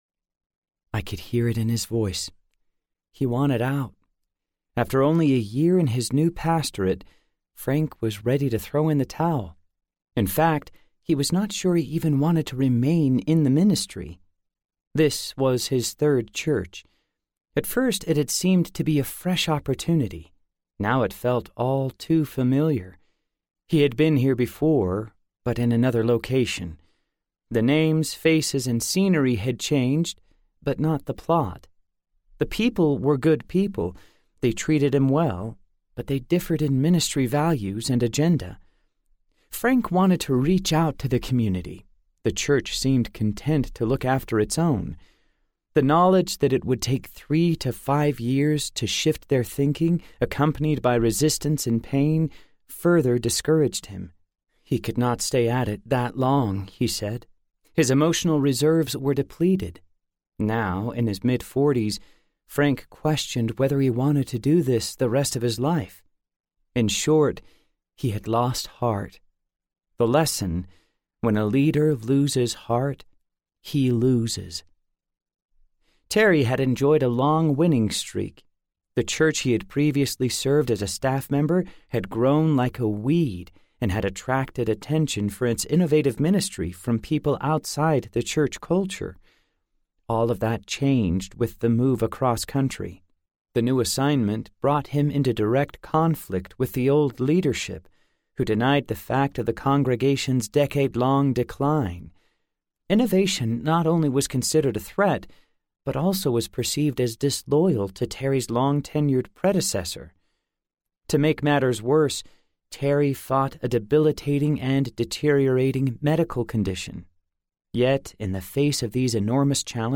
A Work of Heart Audiobook
Narrator